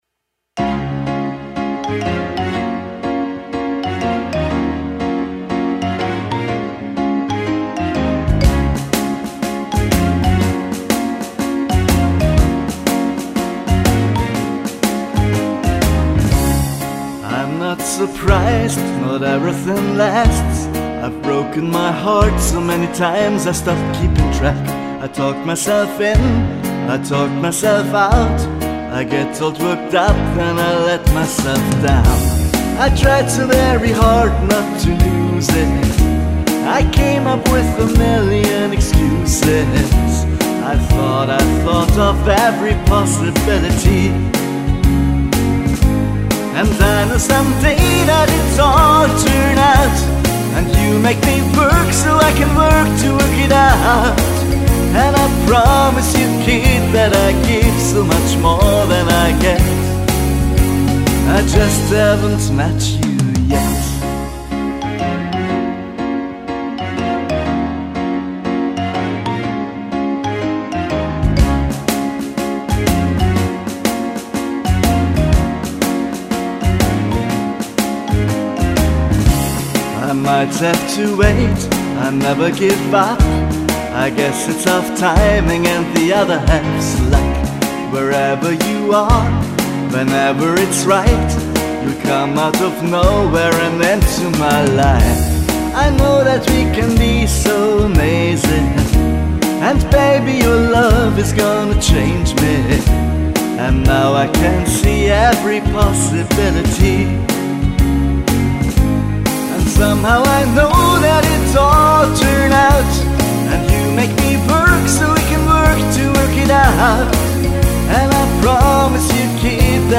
• Alleinunterhalter